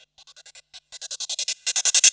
Gate Rev Cym.wav